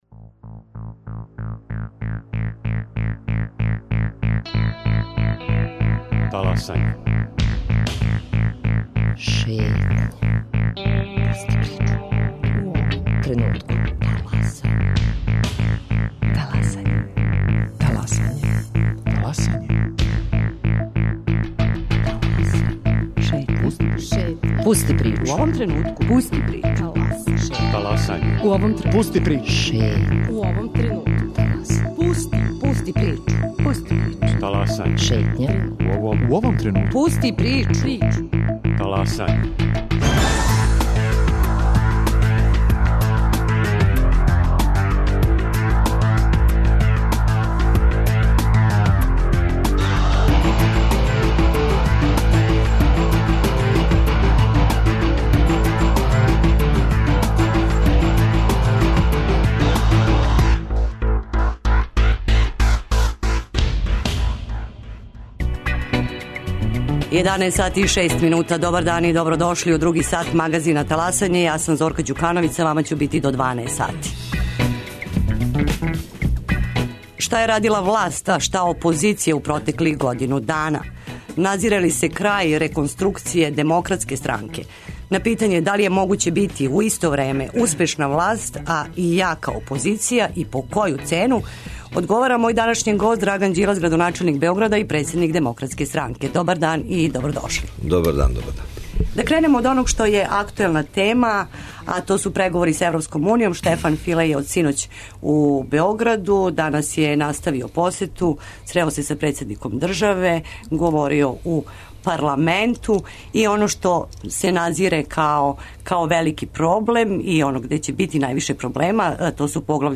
Шта је радила власт, а шта опозиција у протеклих годину дана? Назире ли се крај реконструкције Демократске странке? На питање да ли је могуће бити, у исто време, успешна власт и јака опозиција и по коју цену, одговара Драган Ђилас, градоначелник Београда и председник Демократске странке.